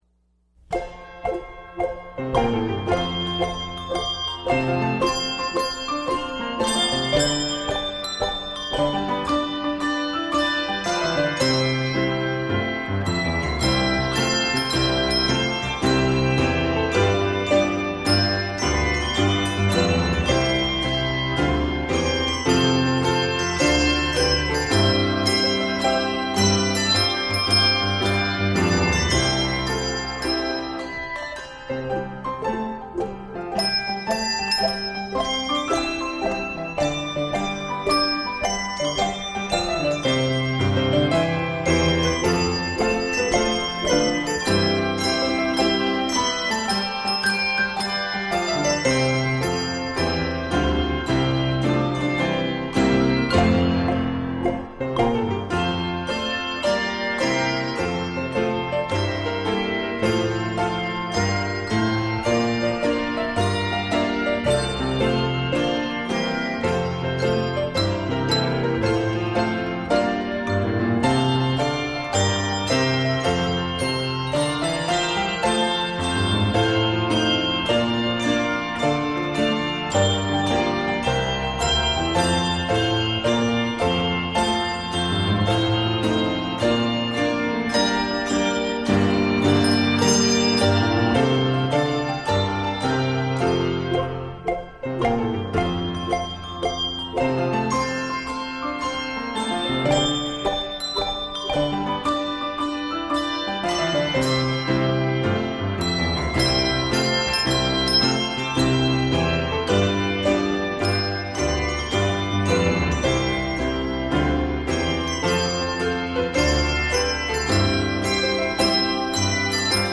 Octaves: 3-5